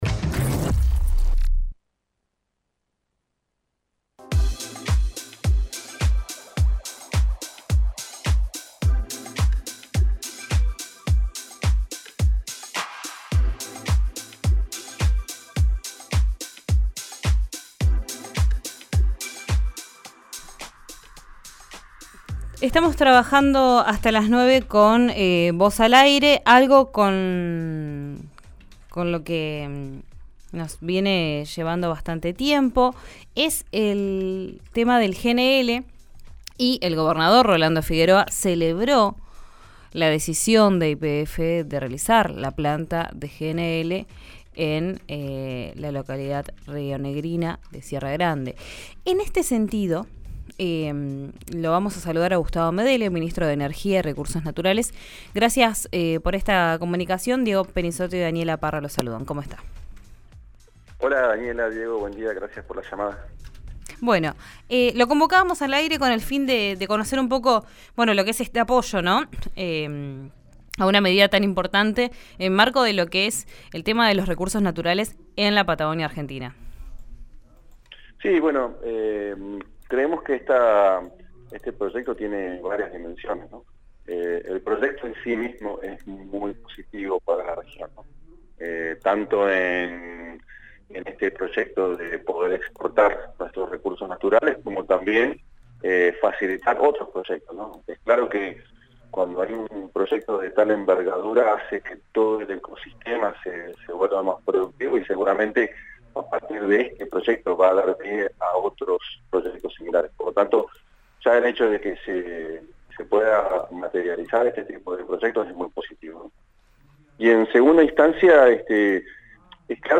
Escuchá al Ministro de Energía, Gustavo Medele, en RÍO NEGRO RADIO:
El ministro de Energía, Gustavo Medele, habló con RÍO NEGRO RADIO sobre la definición oficial de instalar la planta de Gas Natural Licuado (GNL) en Río Negro.